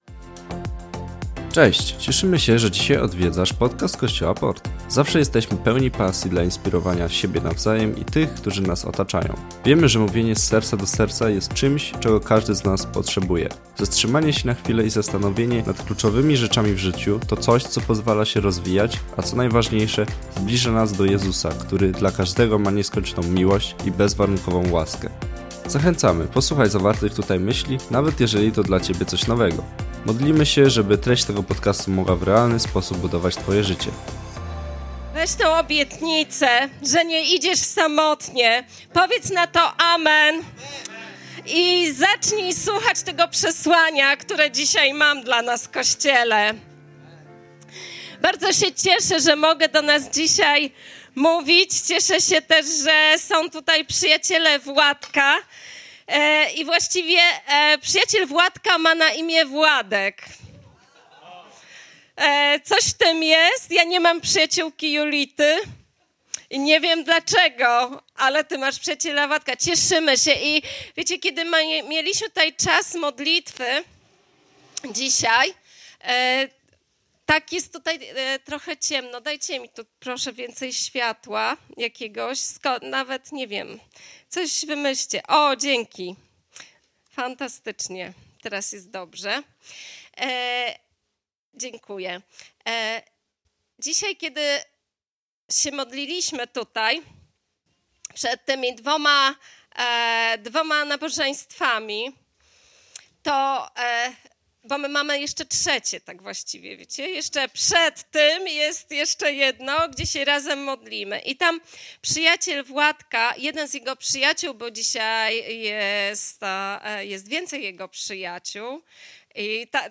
Posłuchaj tego kazania i doświadcz działania Boga w Twoim życiu.